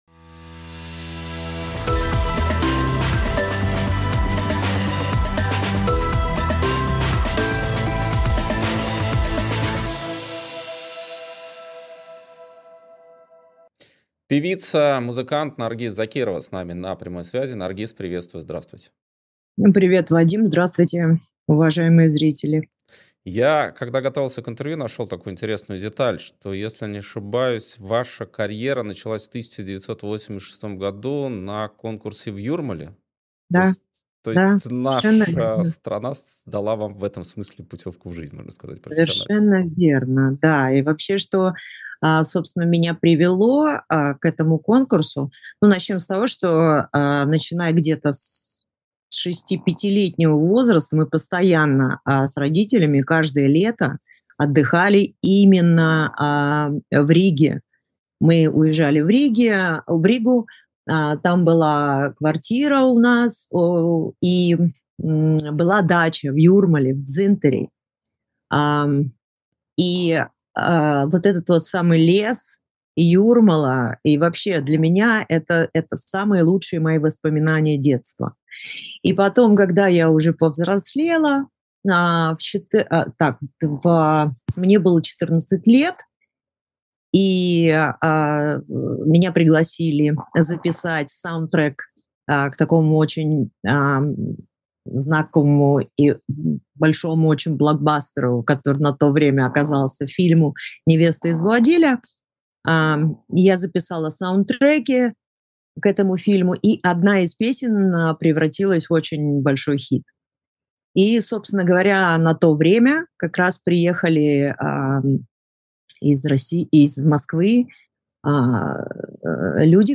Эфир ведёт Вадим Радионов